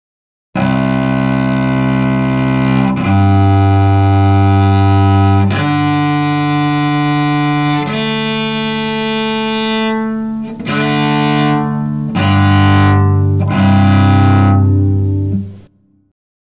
Violonchelo
violoncello.mp3